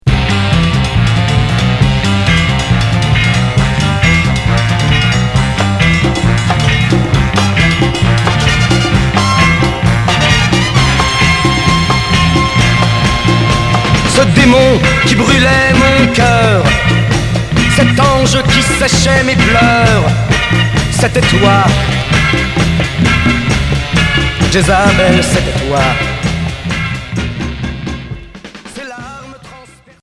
Beat Troisième 45t retour à l'accueil